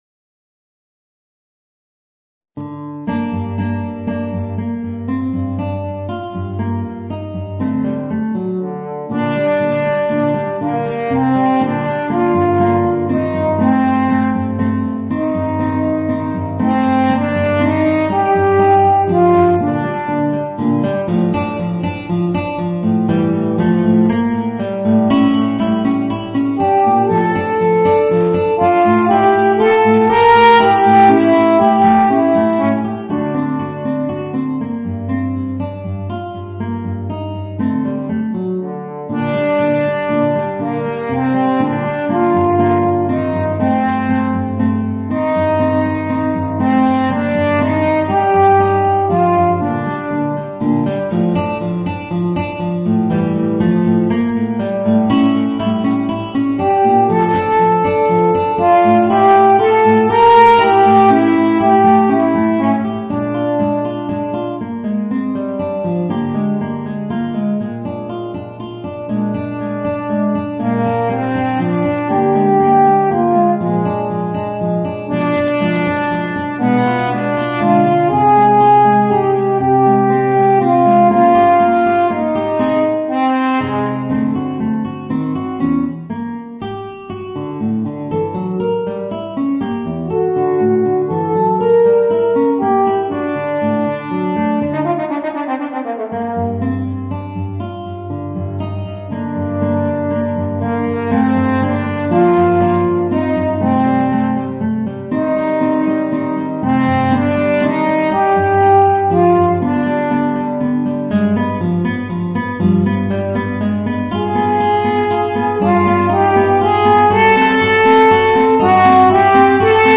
Voicing: Guitar and Eb Horn